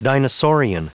Prononciation du mot dinosaurian en anglais (fichier audio)
Prononciation du mot : dinosaurian